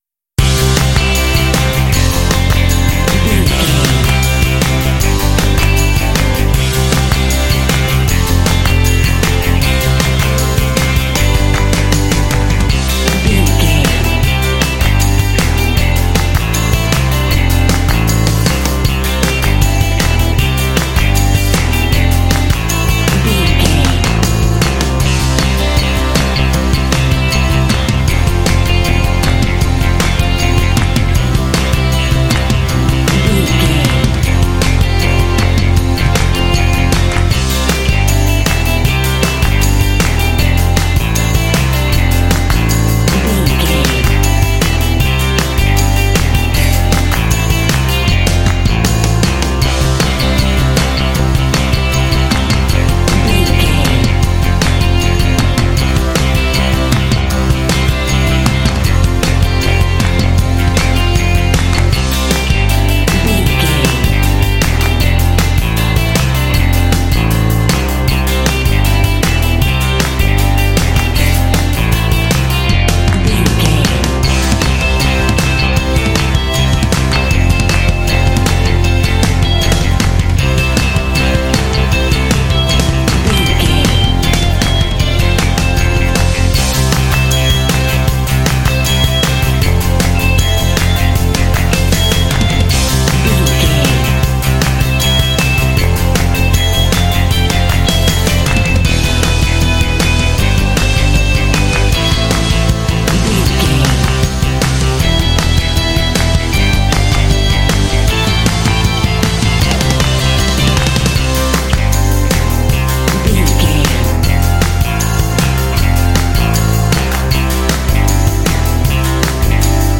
This lively rock track is ideal for action and sports games.
Epic / Action
Uplifting
Ionian/Major
Fast
driving
bouncy
energetic
bass guitar
electric guitar
drums
percussion
synthesiser
classic rock